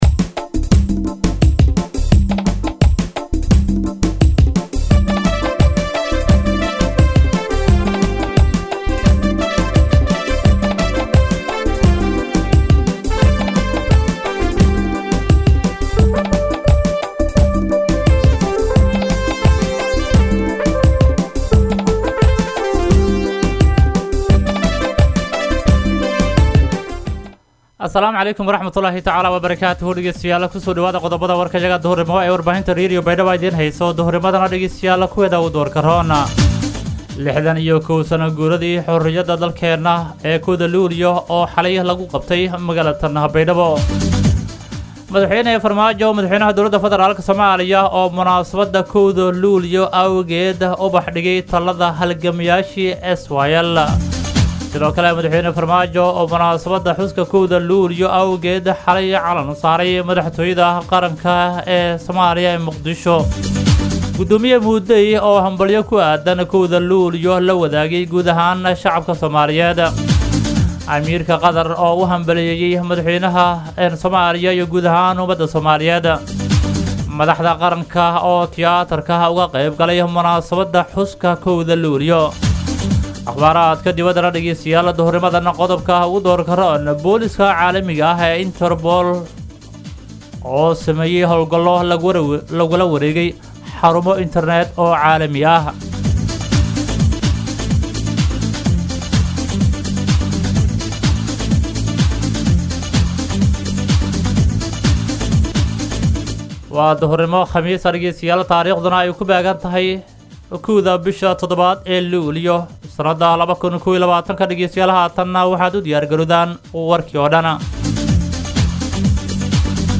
DHAGEYSO:- Warka Duhurnimo Radio Baidoa 1-7-2021
BAYDHABO–BMC:–Dhageystayaasha Radio Baidoa ee ku xiran Website-ka Idaacada Waxaan halkaan ugu soo gudbineynaa Warka ka baxay Radio Baidoa.